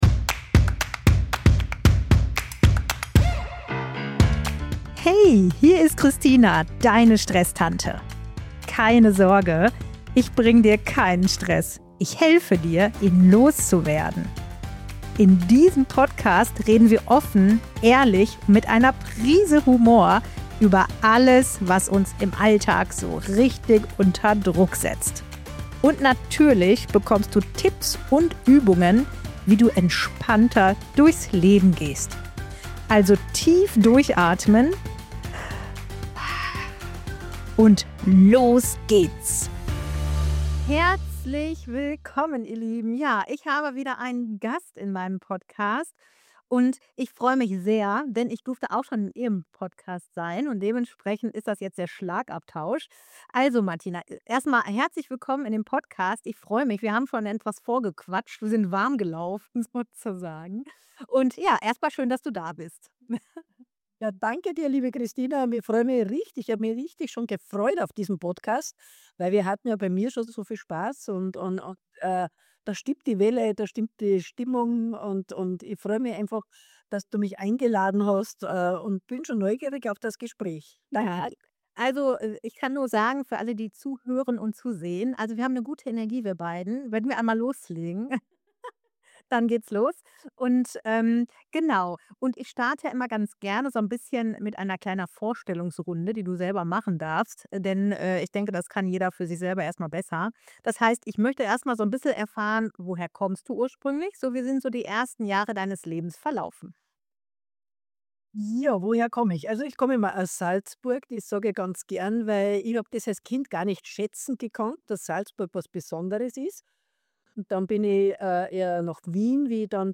Die Stresstante Podcast